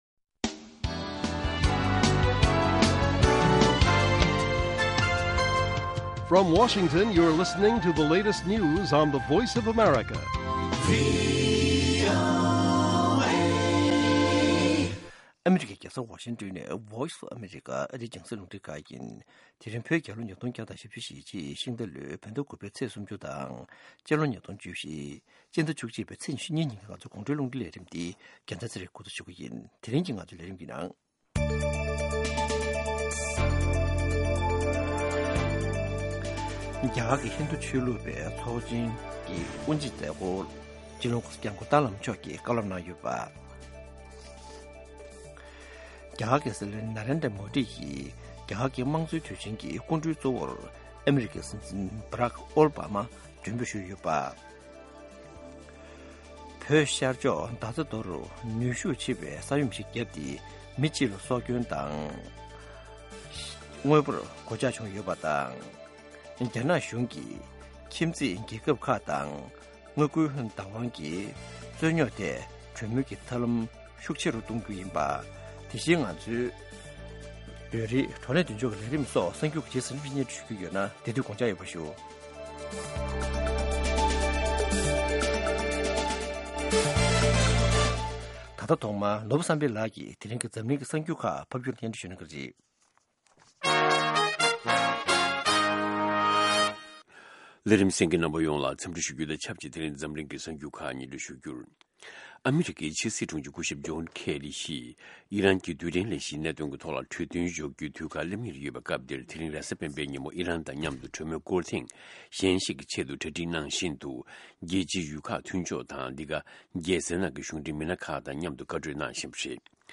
དགོང་དྲོའི་གསར་འགྱུར།